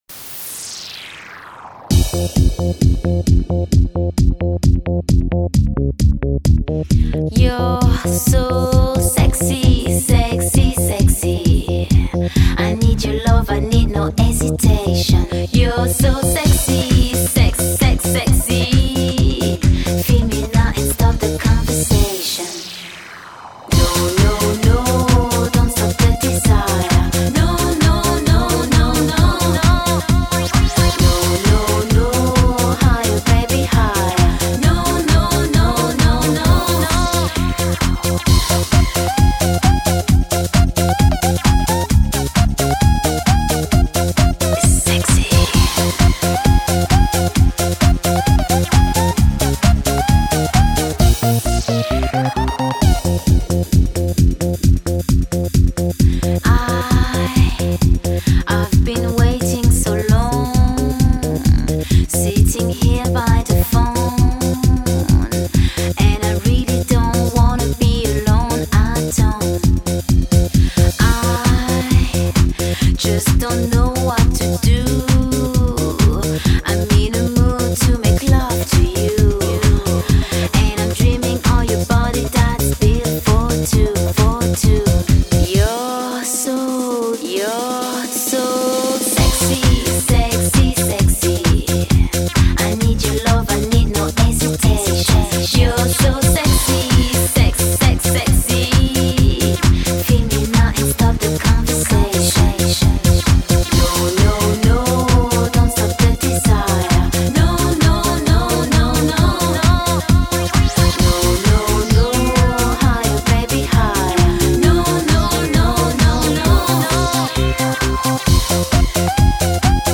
немецко-французский музыкальный поп-проект.